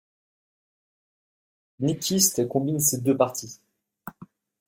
/kɔ̃.bin/